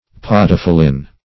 Search Result for " podophyllin" : The Collaborative International Dictionary of English v.0.48: Podophyllin \Pod`o*phyl"lin\, n. [From Podophyllum .]
podophyllin.mp3